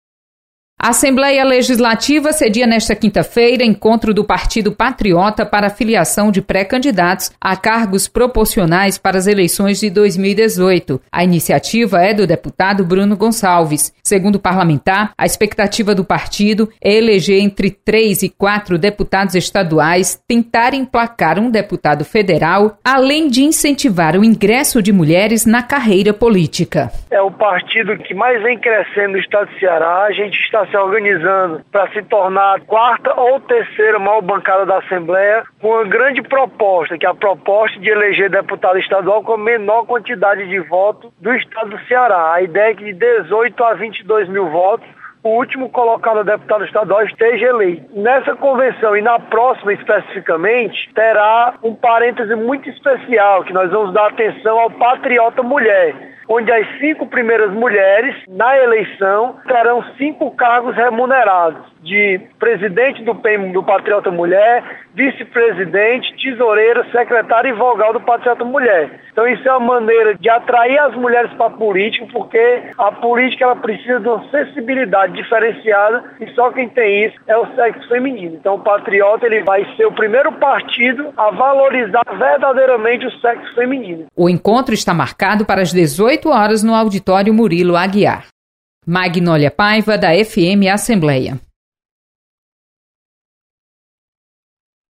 Representantes do Partido Patriota realizam encontro na Assembleia Legislativa. Repórter